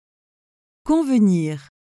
🎧 Convenir pronunciation
kɔ̃vəniʁ/, which sounds like kohn-vuh-neer.